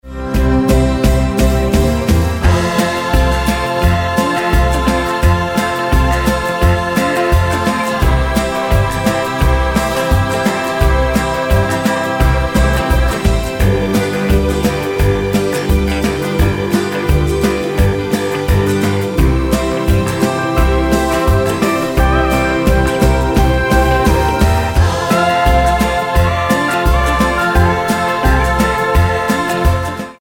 --> MP3 Demo abspielen...
Tonart:Bb-C mit Chor